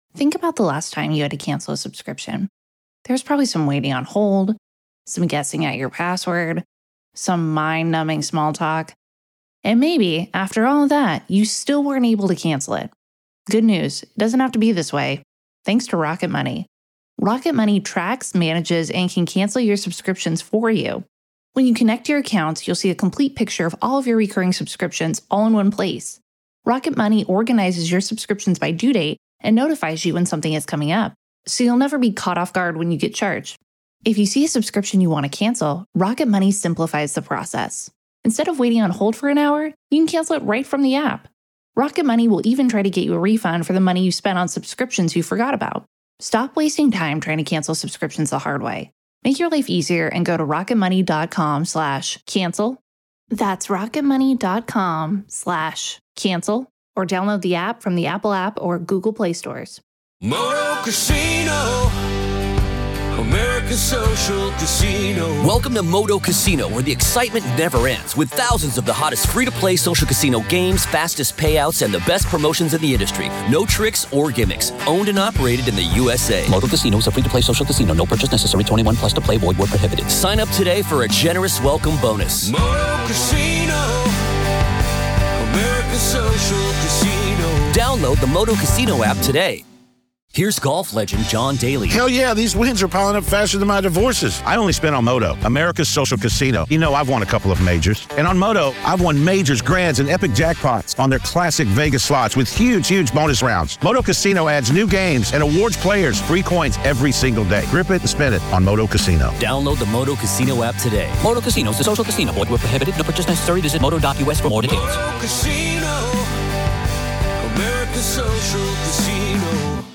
The conversation explores whether Baldwin might not have realized he pulled the trigger due to the complex nature of the scene and the possibility of accidental discharge.